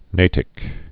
(nātĭk)